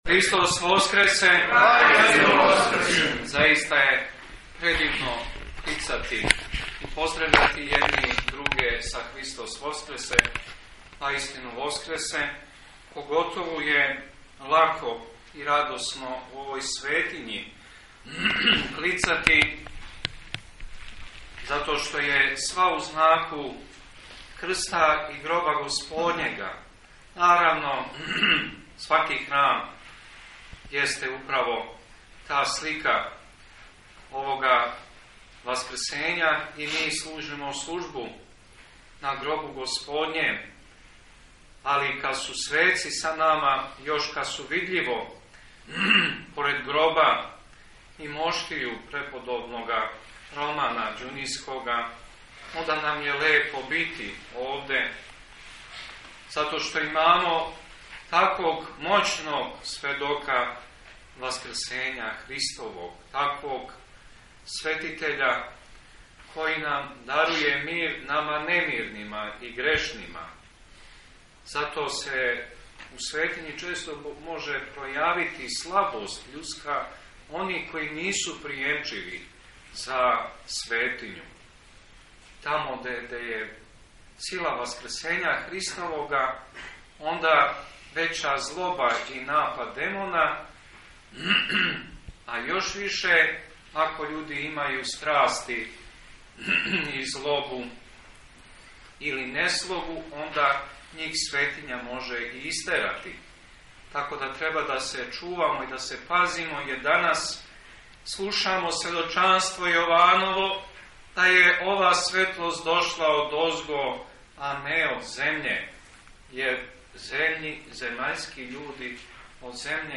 Beseda Episkopa u manastiru Svetog Romana